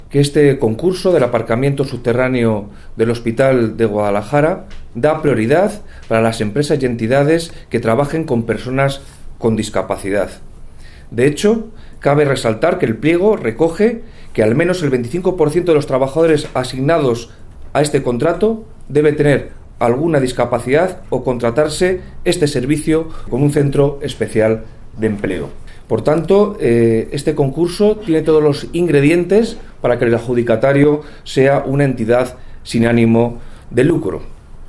El delegado de la Junta en Guadalajara, Alberto Rojo, habla del carácter social del concurso para la adjudicación del parking del Hospital de Guadalajara